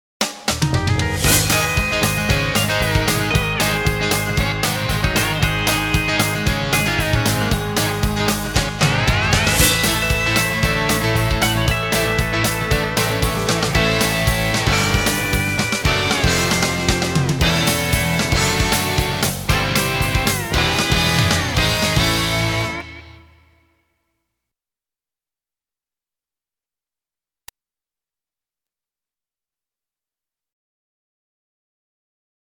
Country Stock Audio Tracks